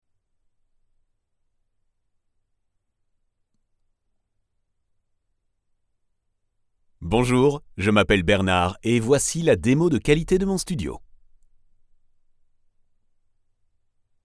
French Male Voice Over Artist
Confident, Corporate, Natural, Reassuring, Warm
Warm & Reassuring professional VO in French for 15+ years.
Audio equipment: StudioBricks booth, RME Babyface interface, CAD EQuitek E100S mic